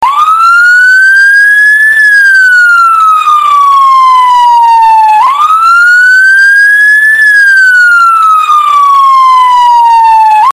2 Police Sirens and Dukes of Hazzard Horn
Just hold down the horn button and they repeat of course.
HORN1.mp3 - 164.9 KB - 4391 views